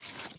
sounds / mob / panda / eat6.ogg
eat6.ogg